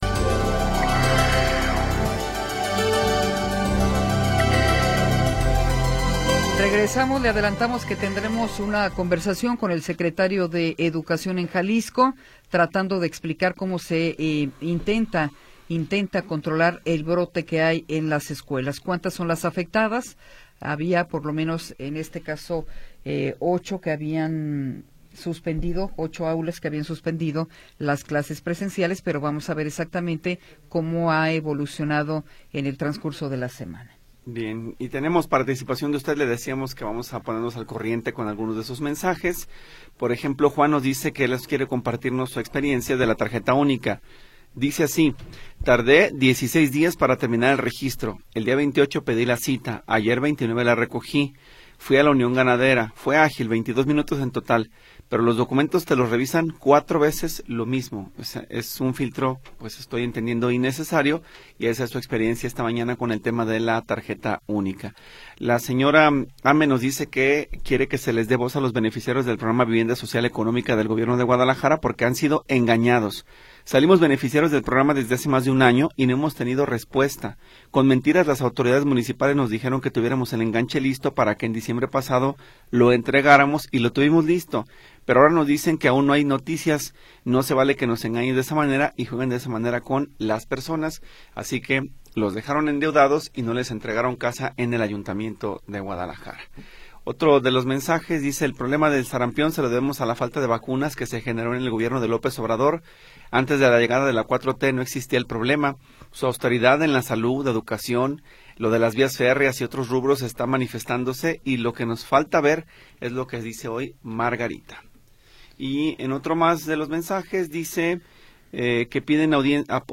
Tercera hora del programa transmitido el 30 de Enero de 2026.